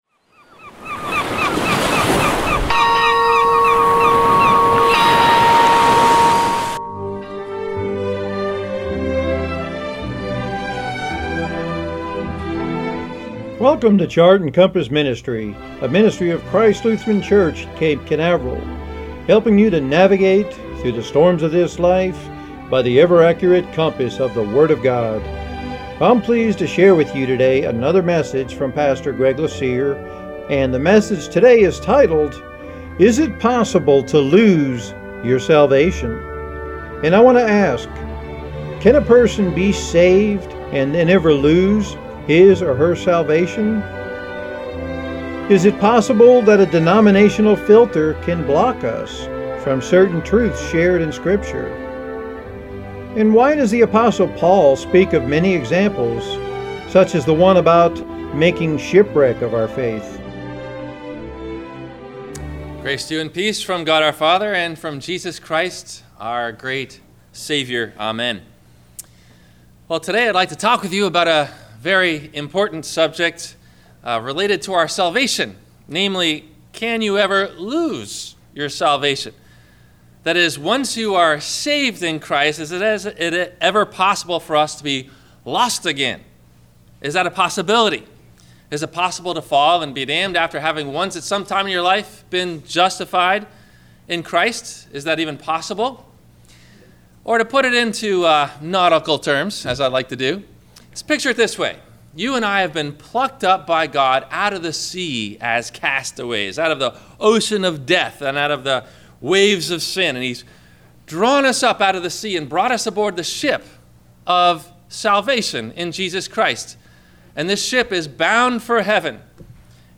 Is it Possible to Lose Your Salvation? – WMIE Radio Sermon – June 06 2016 - Christ Lutheran Cape Canaveral